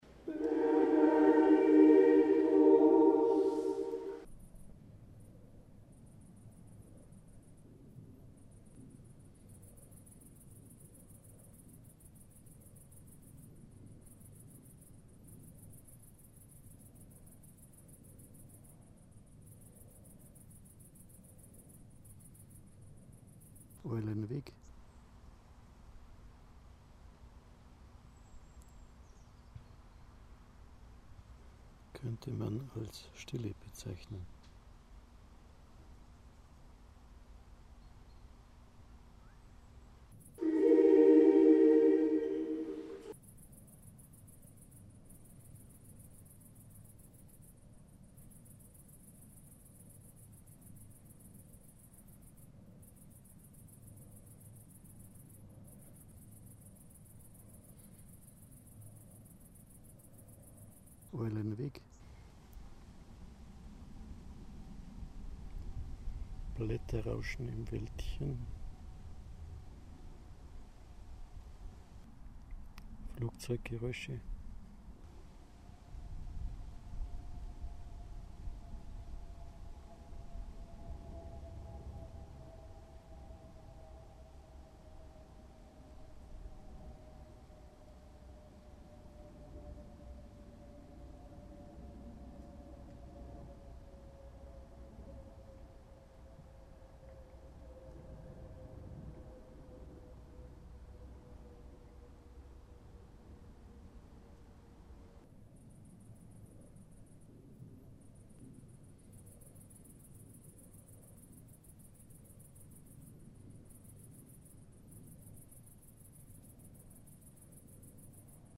Die 4 Anlagen mit 138m Nabenhöhe, 2 weitere abseits 21.07.2011 Vaterstetten - Windkraft im Forst - eigene Exkursion 21.07.2011 Vaterstetten - Windkraft im Forst - eigene Exkursion Private Exkursion zu vergleichbaren WKA westlich von Fürth bei Wilhermsdorf.